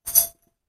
罐装大头针 " 罐装大头针01 - 声音 - 淘声网 - 免费音效素材资源|视频游戏配乐下载
单声道记录玻璃瓶中的小铜拇指扣，为游戏项目所用。